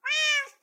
mob / cat / meow4.ogg
meow4.ogg